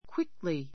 quickly 中 A1 kwíkli ク ウィ ク り 副詞 比較級 more quickly 最上級 most quickly すばやく , 速く, 急いで , すぐに 反対語 slowly （ゆっくり） I walked quickly.